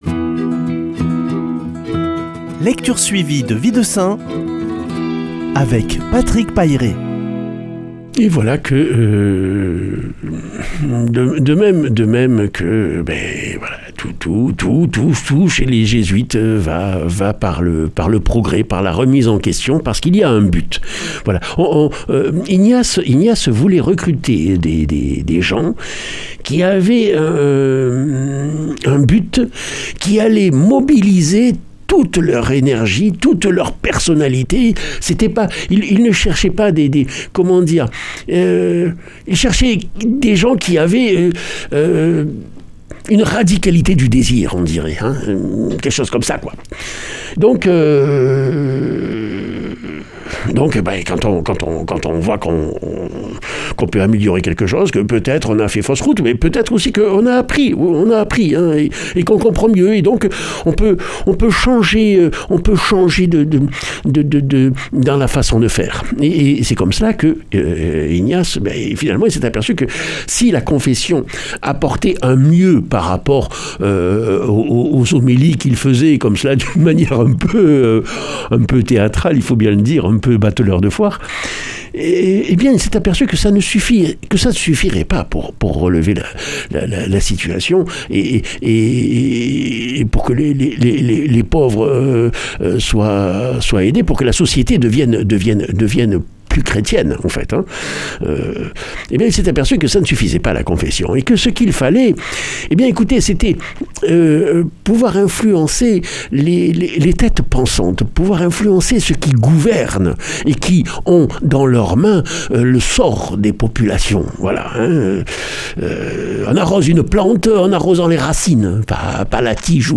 Lecture suivie de la vie des saints